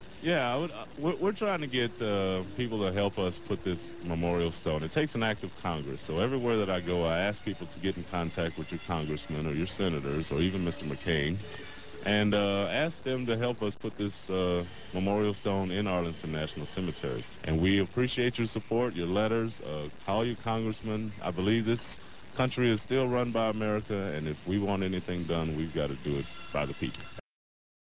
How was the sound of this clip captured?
at KYJT radio Yuma, AZ